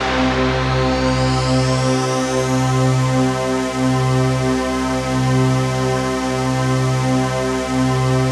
CHRDPAD021-LR.wav